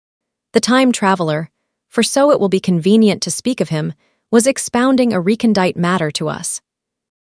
Kokoro-FastAPI - Dockerized FastAPI wrapper for Kokoro-82M text-to-speech model w/CPU ONNX and NVIDIA GPU PyTorch support, handling, and auto-stitching